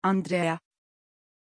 Pronuncia di Andreea
pronunciation-andreea-tr.mp3